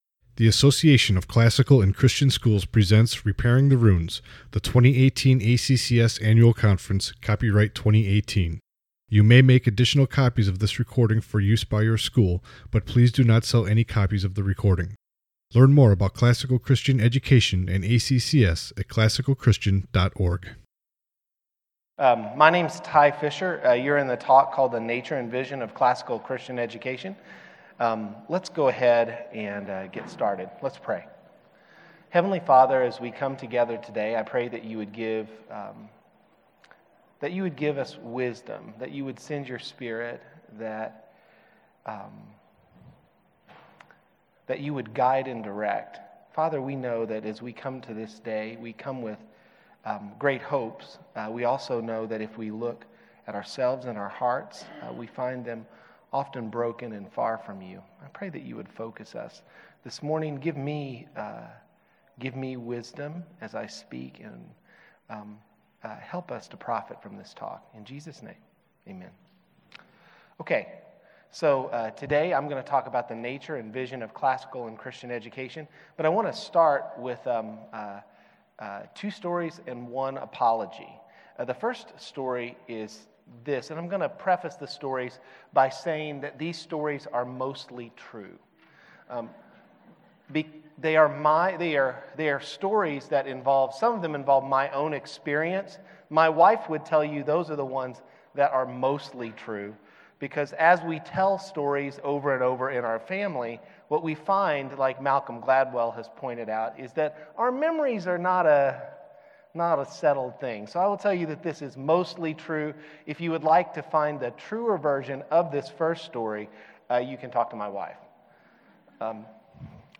2018 Foundations Talk | 59:05 | All Grade Levels, General Classroom
Jan 15, 2019 | All Grade Levels, Conference Talks, Foundations Talk, General Classroom, Library, Media_Audio | 0 comments
Additional Materials The Association of Classical & Christian Schools presents Repairing the Ruins, the ACCS annual conference, copyright ACCS.